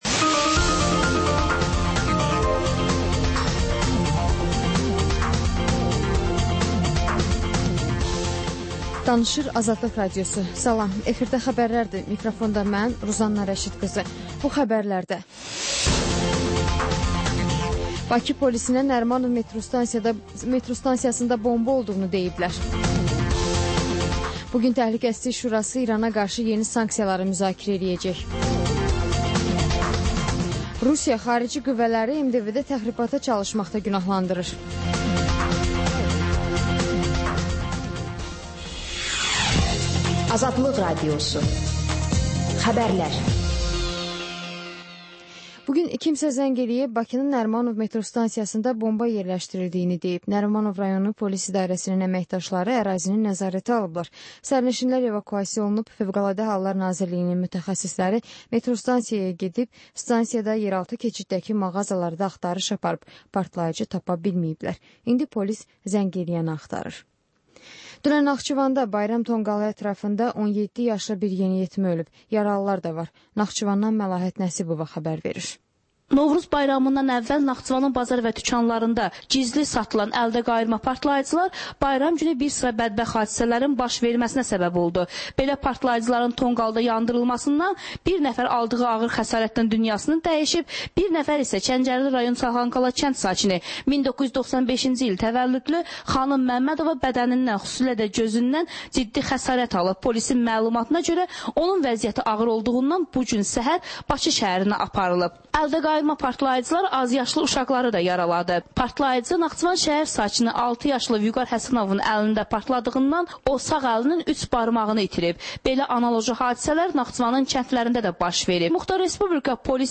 Xəbərlər, müsahibələr, hadisələrin müzakirəsi, təhlillər, sonda 14-24: Gənclər üçün xüsusi veriliş